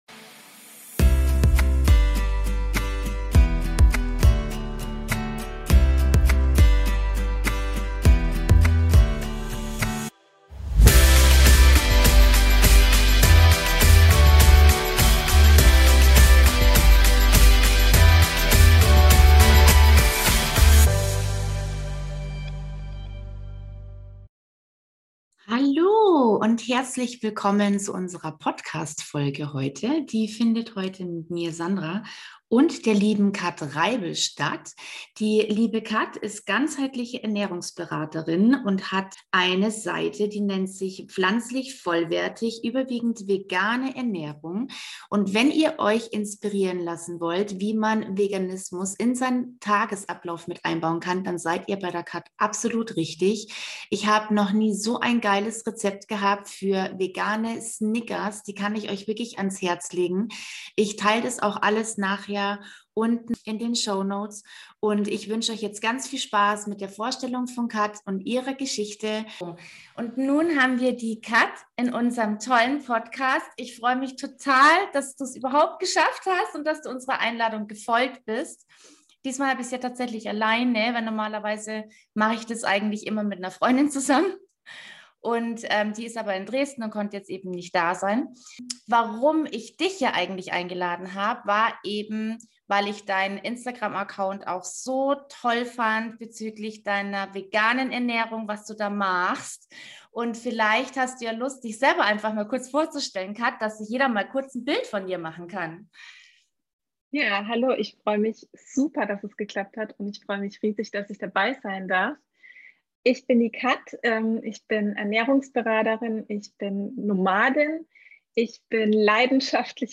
#7 Vegane Ernährung als Lebensentscheidung - Interview